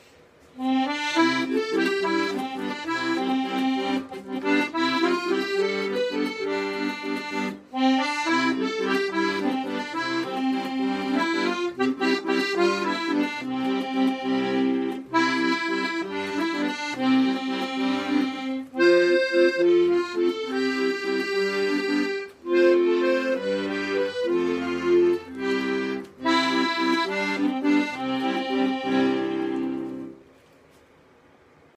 Trekkspill_Come_to_the_Sea.mp3